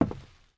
Footsteps_Wood_Walk_04.wav